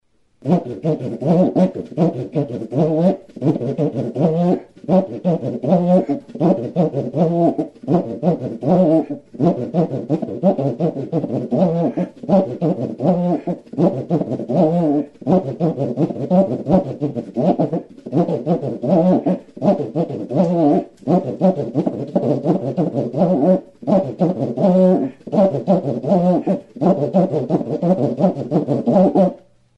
Membranófonos -> Friccionados / frotados -> Barita
Grabado con este instrumento.
ZAMBOMBA